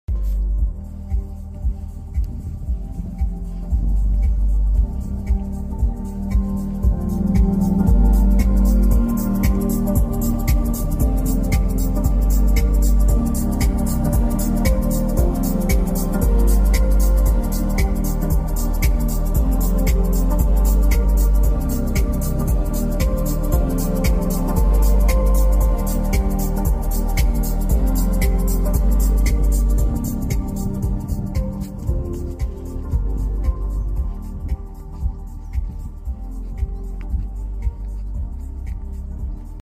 The Everest Platinum is proving its worth with the smooth 3.0L V6 diesel engine that transforms it from just adventure to fitting into luxury segment. As usual, I share the premium sound systems and the 10-Speaker B&O sound system in here does provide smooth details even at the highest volume.